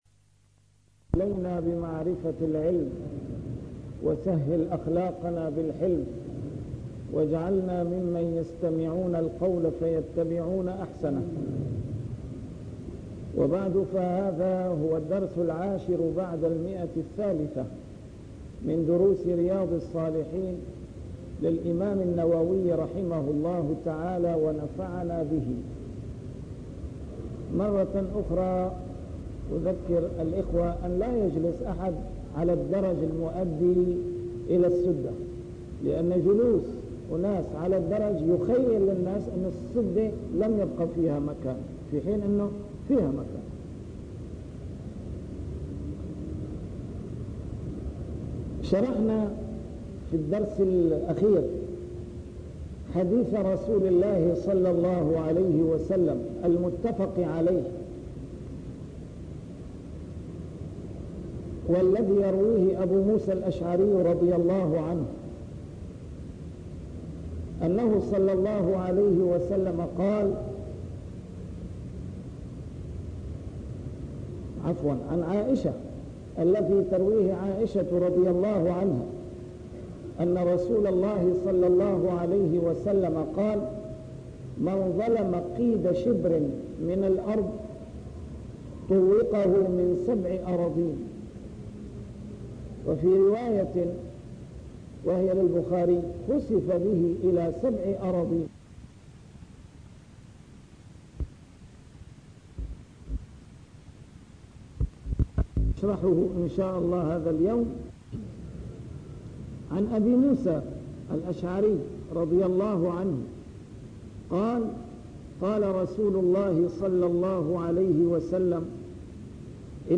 A MARTYR SCHOLAR: IMAM MUHAMMAD SAEED RAMADAN AL-BOUTI - الدروس العلمية - شرح كتاب رياض الصالحين - 310- شرح رياض الصالحين: تحريم الظلم